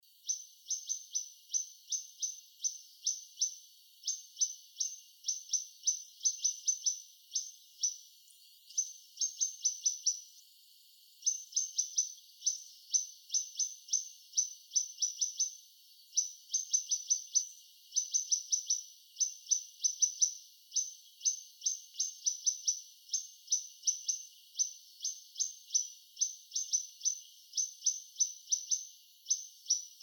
雀鳥聲音
Soundclip2_Fork-tailed_Sunbird.mp3